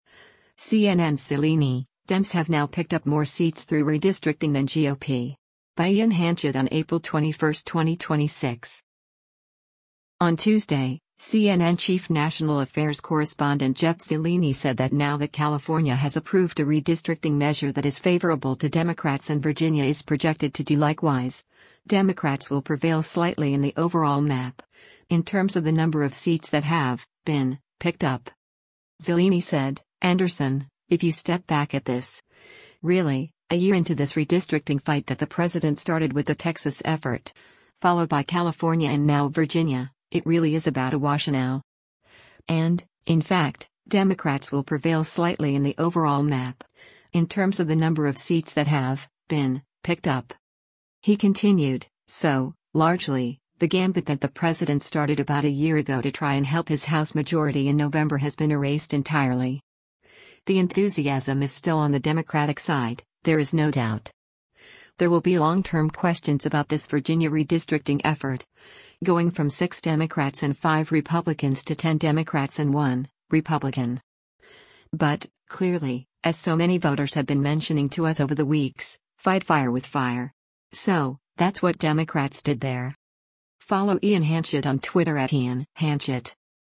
On Tuesday, CNN Chief National Affairs Correspondent Jeff Zeleny said that now that California has approved a redistricting measure that is favorable to Democrats and Virginia is projected to do likewise, “Democrats will prevail slightly in the overall map, in terms of the number of seats that have [been] picked up.”